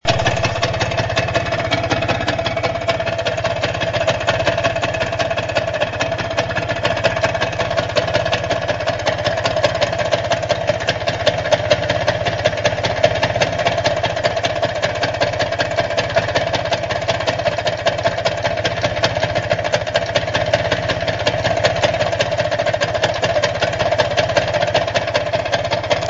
Motorgeräusch - beim Ziehen der Kupplung auf einmal weg - VespaOnline Forum: Entdecke die Welt der klassischen Vespa-Roller und teile deine Leidenschaft
Ich habe seit langem ein komisches "klimperndes" Geräusch bei meiner PK 50s mit 75er DR (3 Überströmer)...